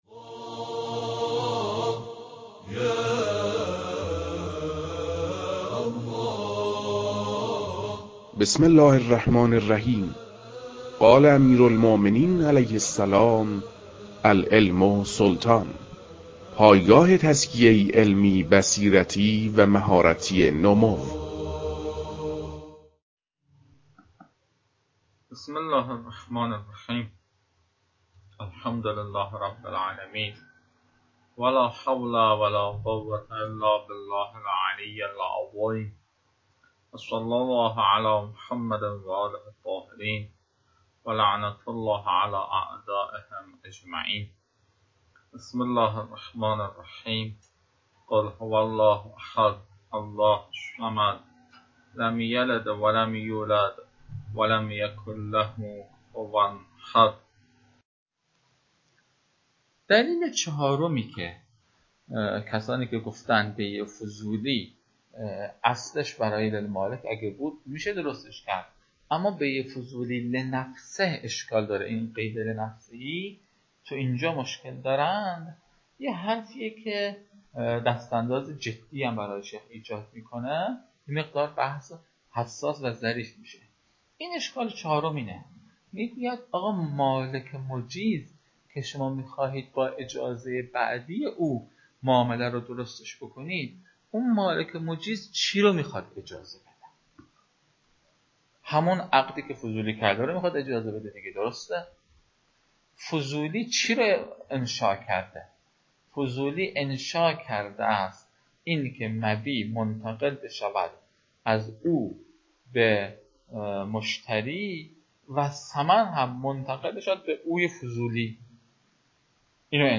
در این بخش، فایل های مربوط به تدریس مباحث تنبیهات معاطات از كتاب المكاسب